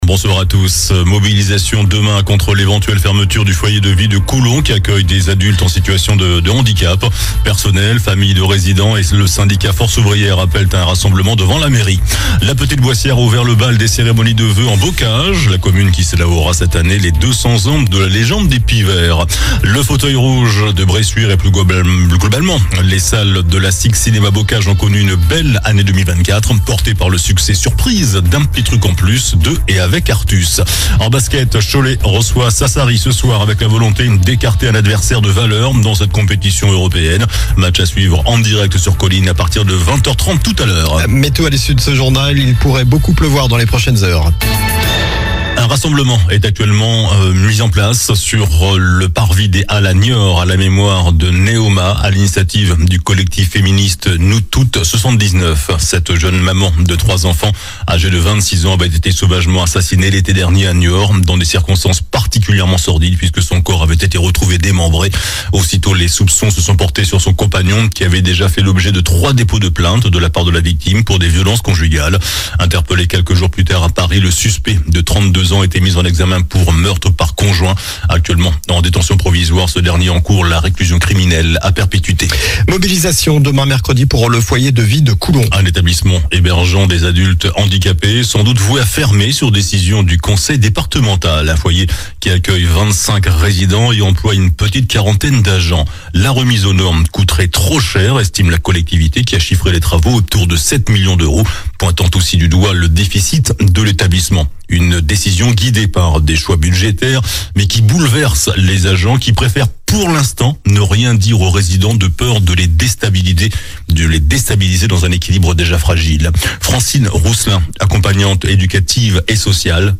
JOURNAL DU MARDI 07 JANVIER ( SOIR )